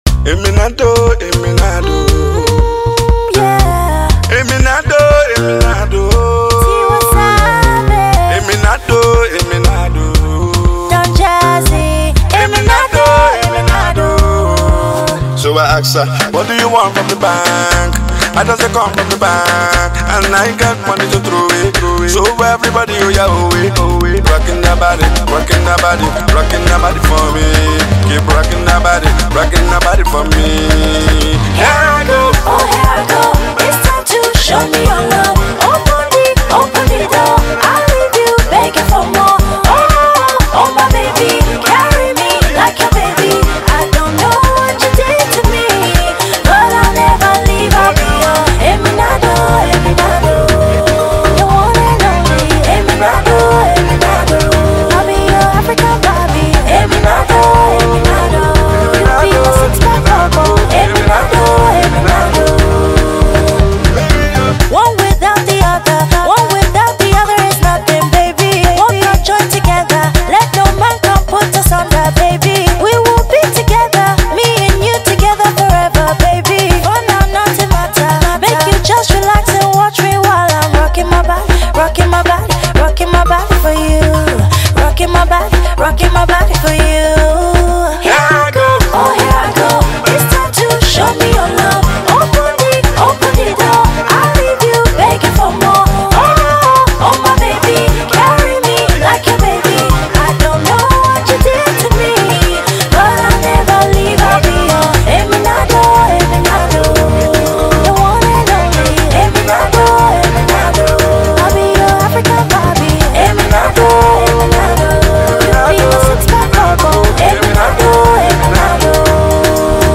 steps in with a confident verse